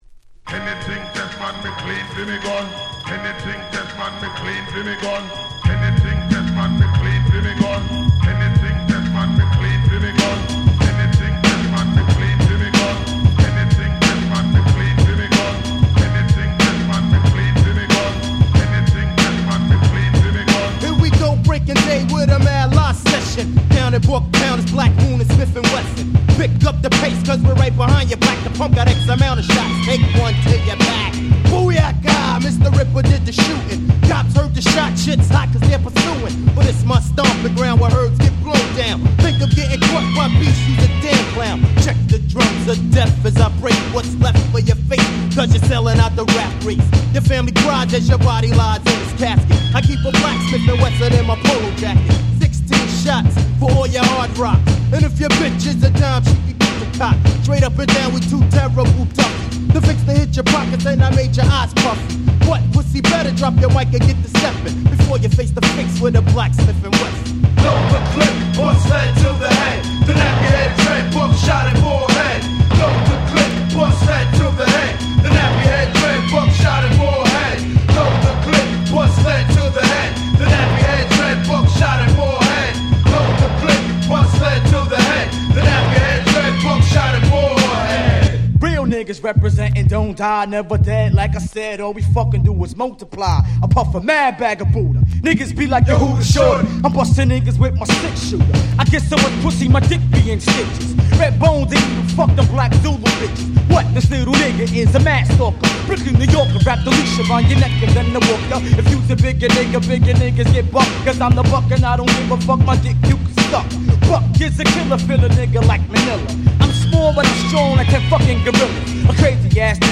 95' Smash Hit Hip Hop / Underground Hip Hop !!